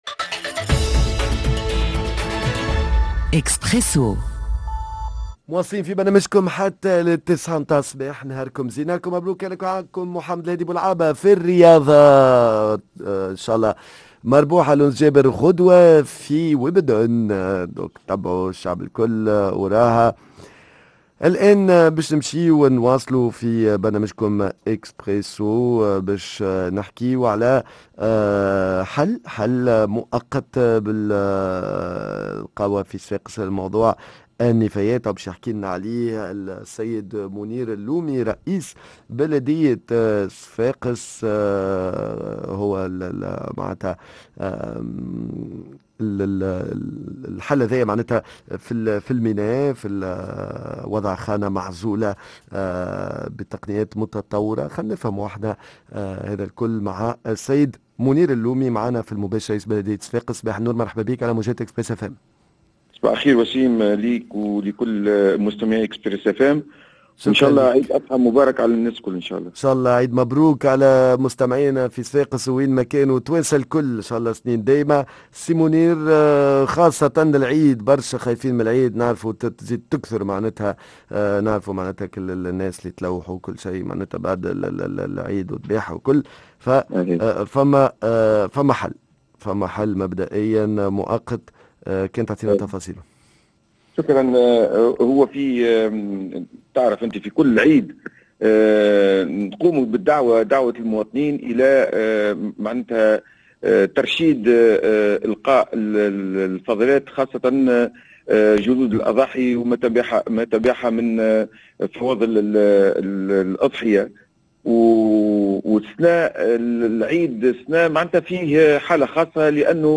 كيفاش باش تواجه ولاية صفاقس كمية الفضلات المتعلقة بالعيد ، خاصة في ظل أزمة النفايات الي تعيشها ضيفنا منير اللومي رئيس بلدية صفاقس